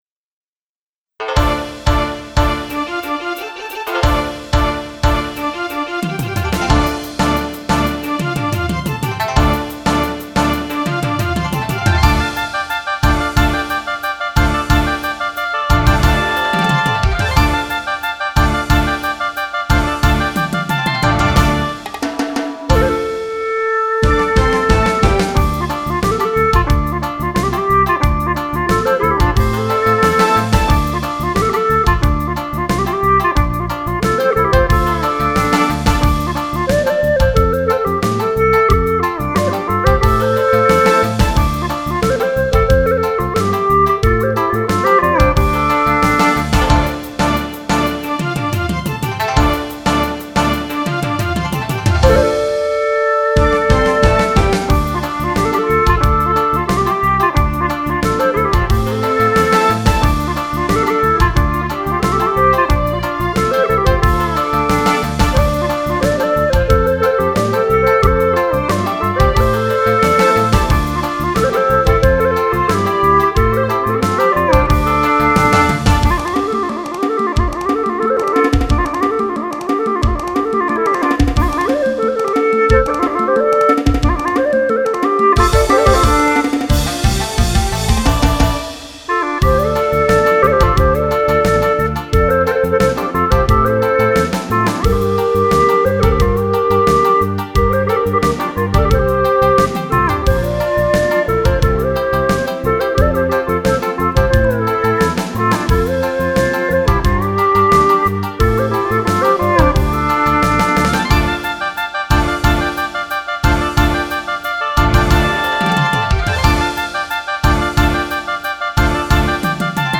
调式 : F